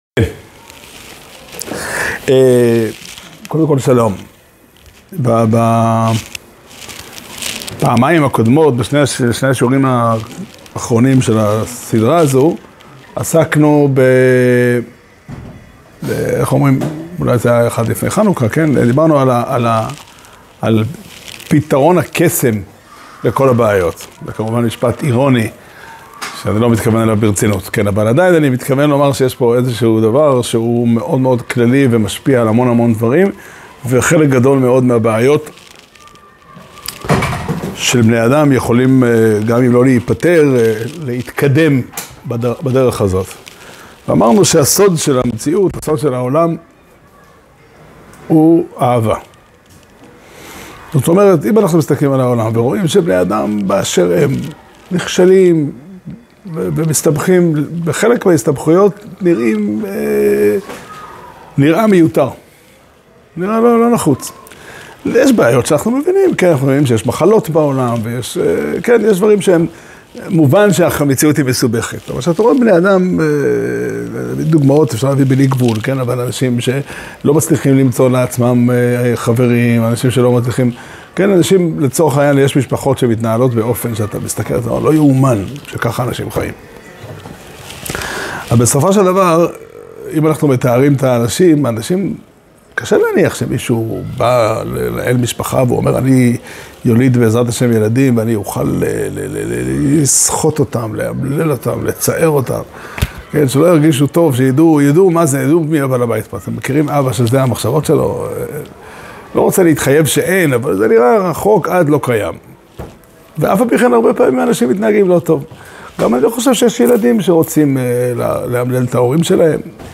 שיעור שנמסר בבית המדרש פתחי עולם בתאריך ט' טבת תשפ"ה